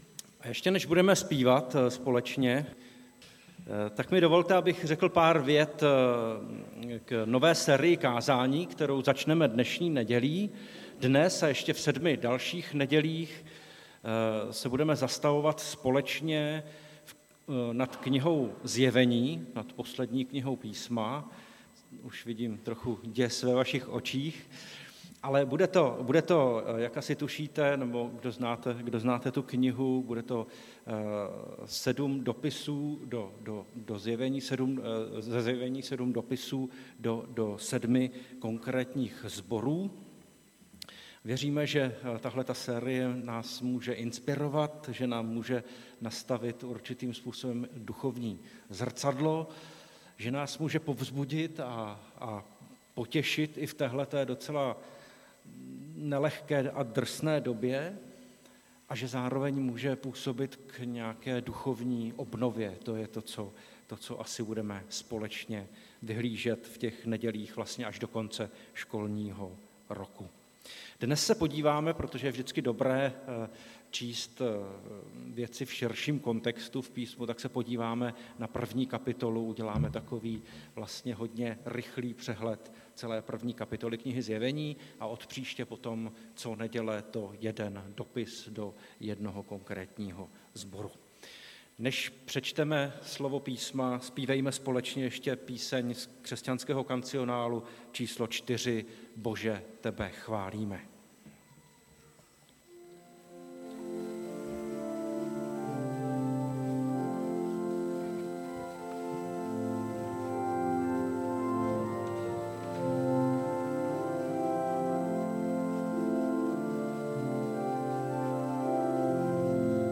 Nedělní kázání – 24.4.2022 Ten, který byl a který jest